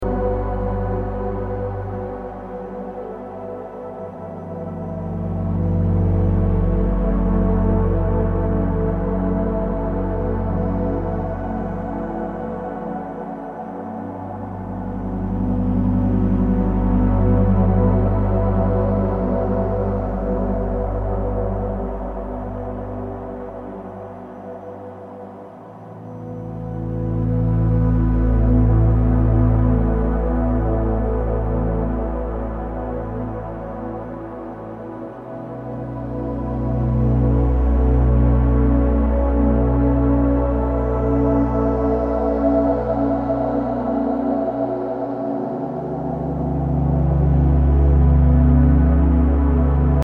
Ambient, Drone >